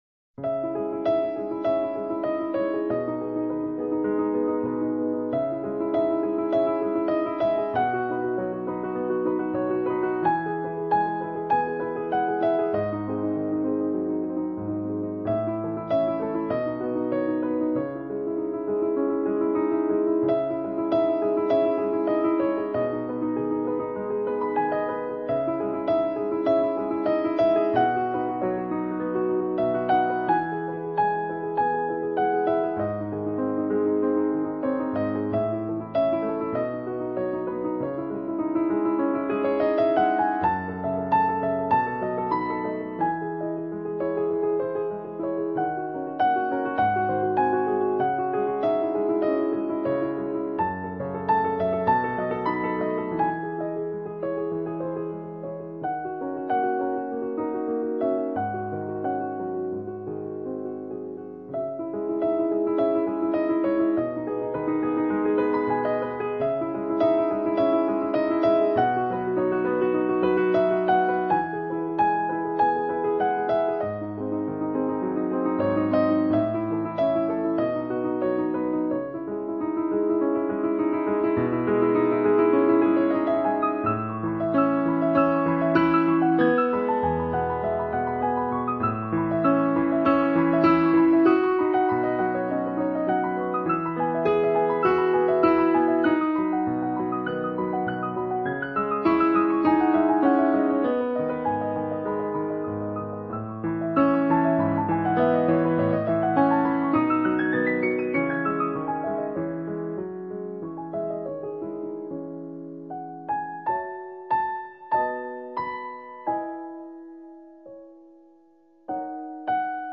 优美旋律 灵性编曲 印象派诗画 NEW AGE況味 爵士心情